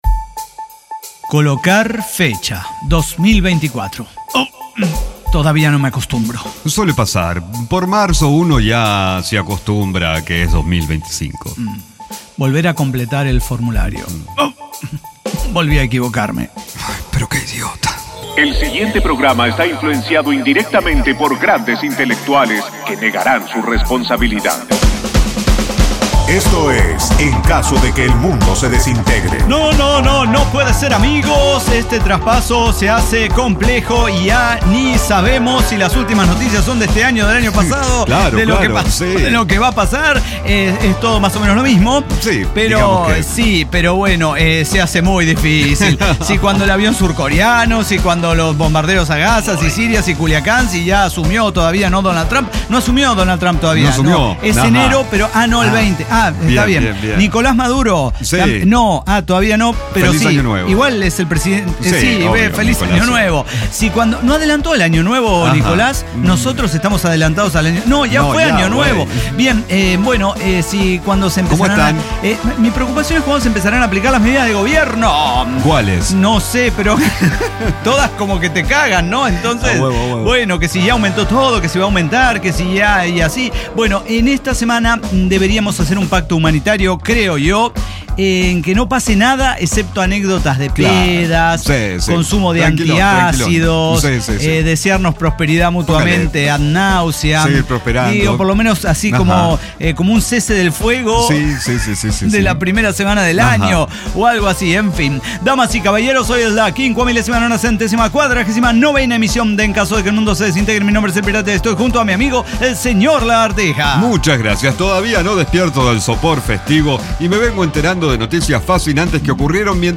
5949 El Estado Soy Yo – ECDQEMSD jueves, 2 de enero de 2025 Bonito momento iniciando el año con una conversación sobre las funciones del Estado y su relación con los privados.
Diseño, guionado, música, edición y voces son de nuestra completa intervención humana.